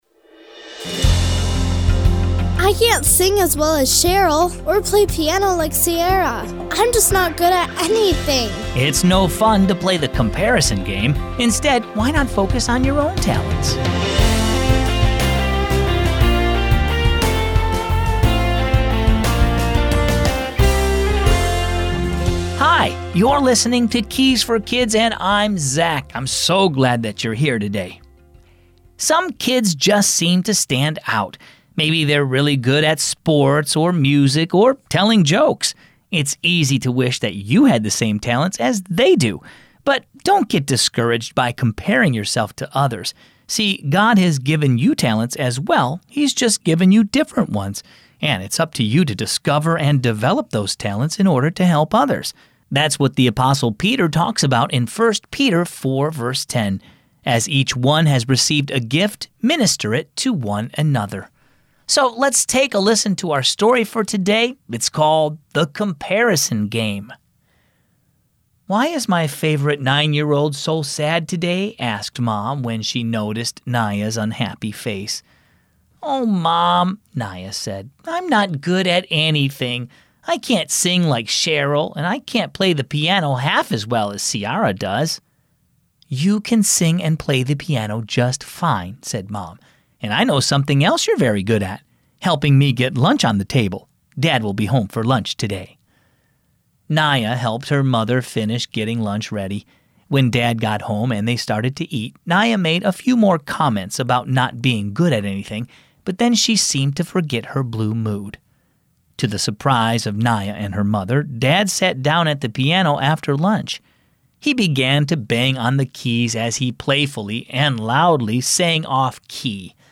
Keys for Kids - daily devotions and Bible stories for kids and families